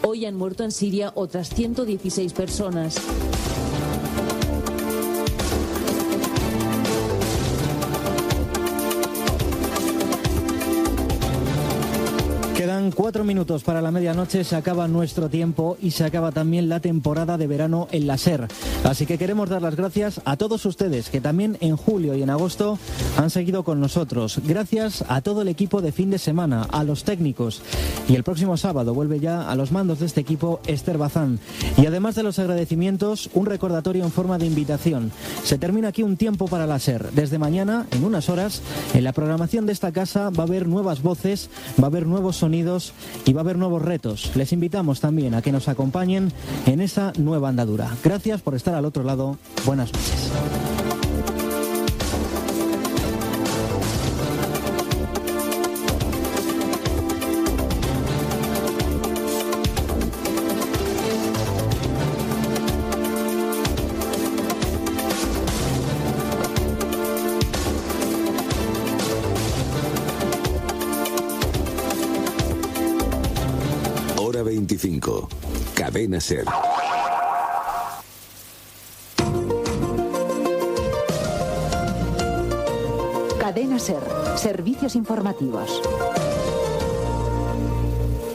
Comiat del programa amb esment als canvis i novetats a la Cadena SER. Indicatiu del programa.
Informatiu
Darrer dia d'emissió de la sintonia genèrica d'"Hora 25" i d'aquesta careta dels Serveis Informatius de la Cadena SER.